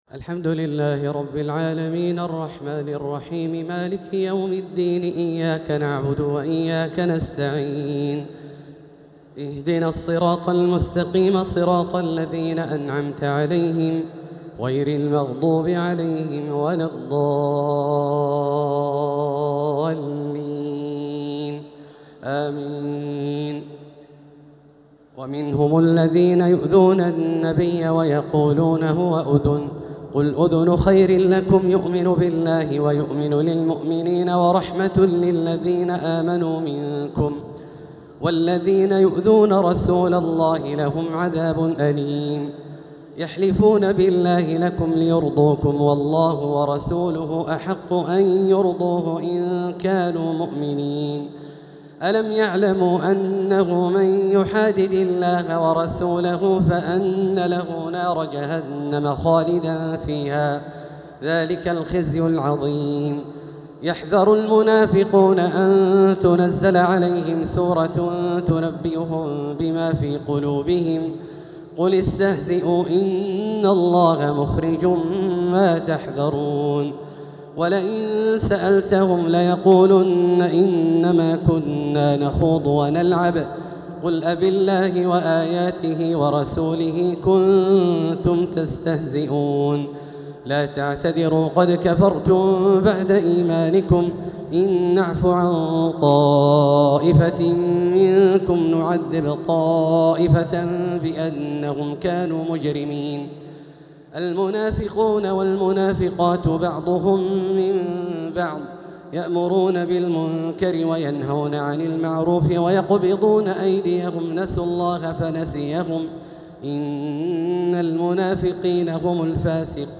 تلاوة من سورة التوبة (61-116) | تراويح ليلة 14 رمضان 1447هـ > تراويح 1447هـ > التراويح - تلاوات عبدالله الجهني